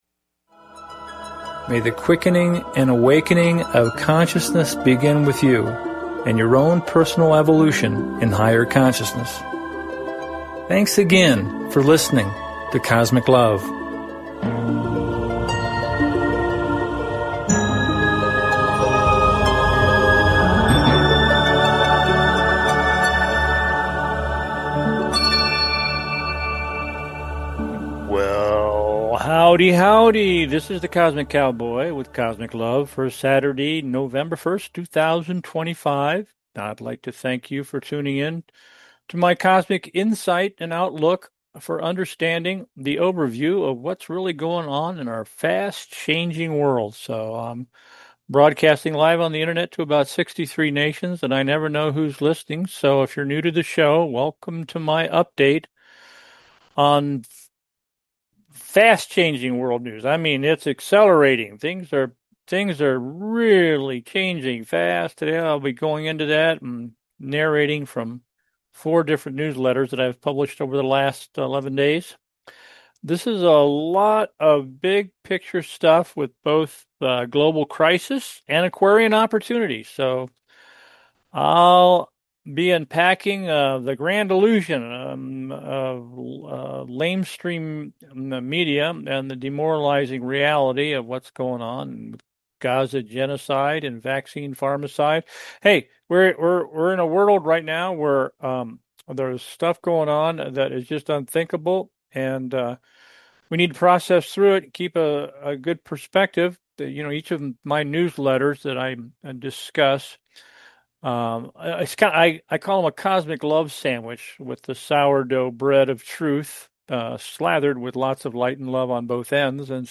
Cosmic LOVE Talk Show